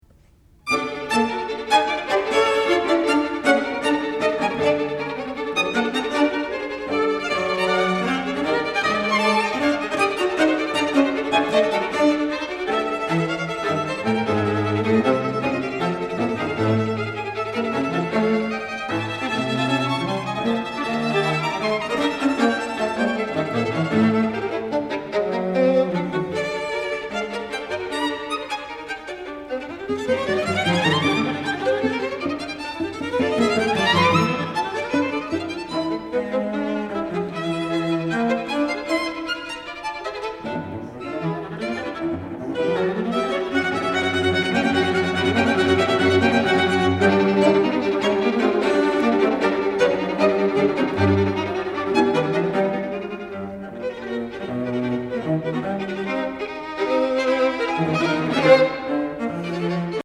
violon
alto
violoncelle